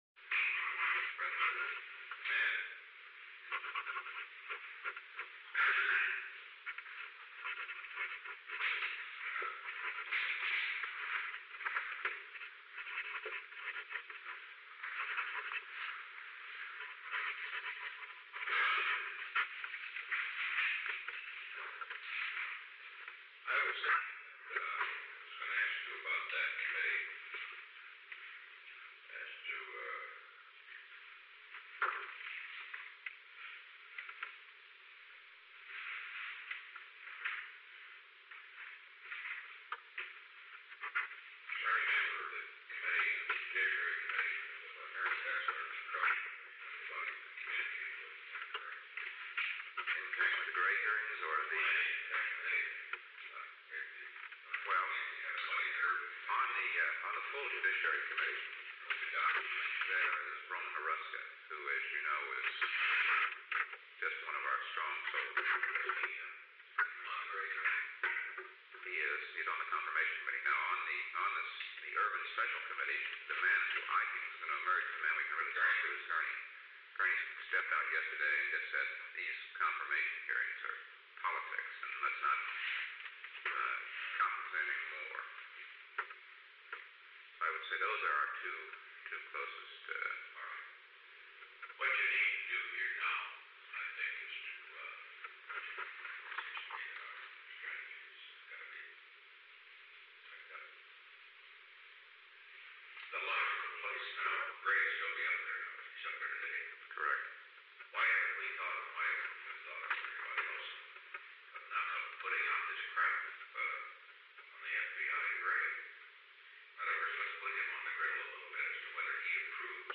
Secret White House Tapes | Richard M. Nixon Presidency 871-004a